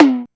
Tom10.aif